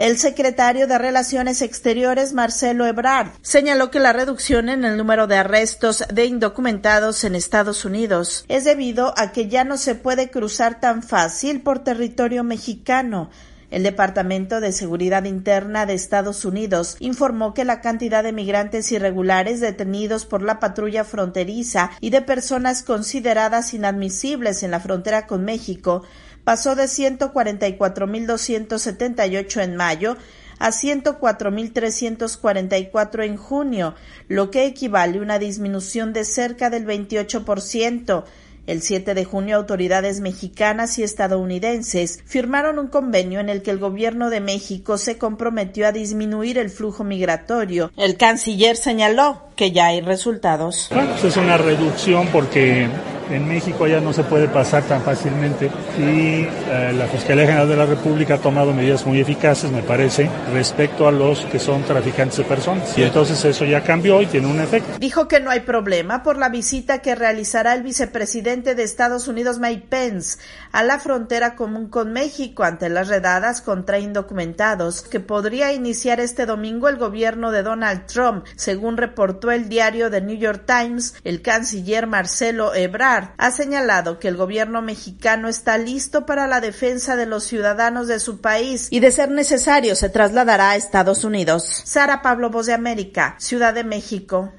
Autoridades mexicanas aseguran que hay resultados del plan que busca disminuir los flujos migratorios hacia EE.UU. Informa la corresponsal de la Voz de América